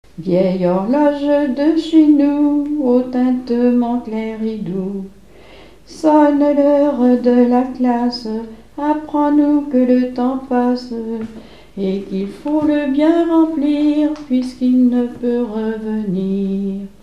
Mémoires et Patrimoines vivants - RaddO est une base de données d'archives iconographiques et sonores.
Genre strophique
Pièce musicale inédite